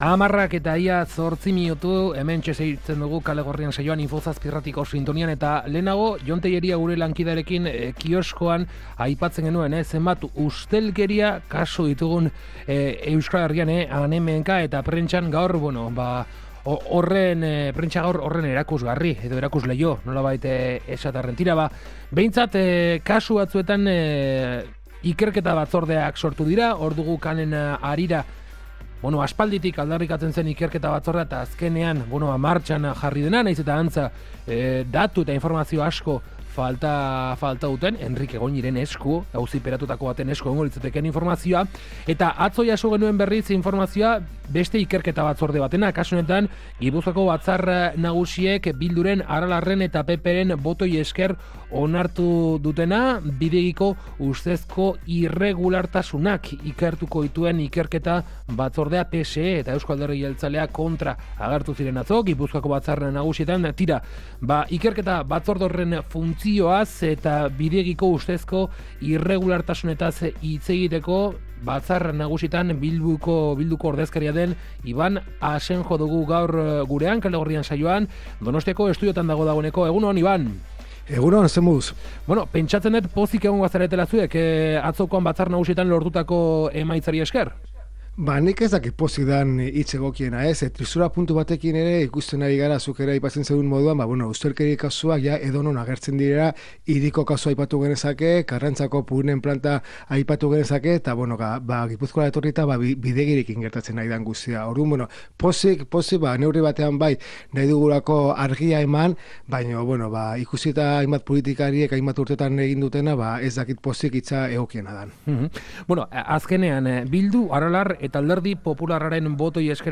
Honetaz guztiaz solastu dugu gaur Iban Asenjorekin, Gipuzkoako Batzar Nagusietako Bilduren bozeramalearekin.